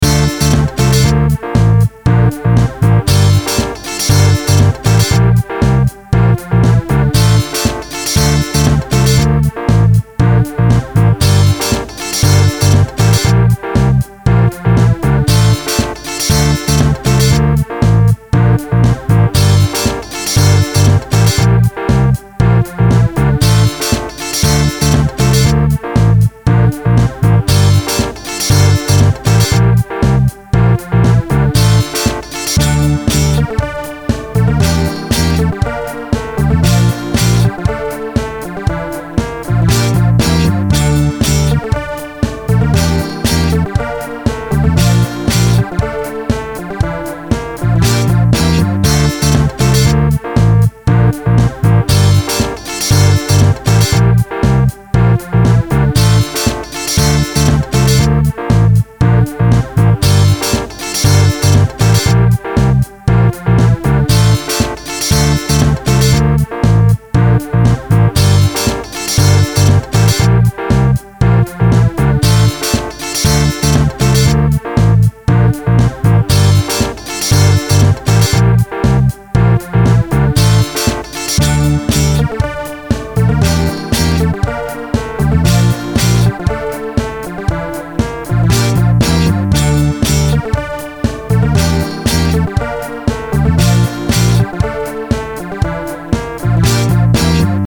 I think I just wanted to play guitar.